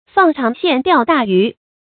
放長線釣大魚 注音： ㄈㄤˋ ㄔㄤˊ ㄒㄧㄢˋ ㄉㄧㄠˋ ㄉㄚˋ ㄧㄩˊ 讀音讀法： 意思解釋： 比喻做事從長遠打算，雖然不能立刻收效，但將來能得到更大的好處。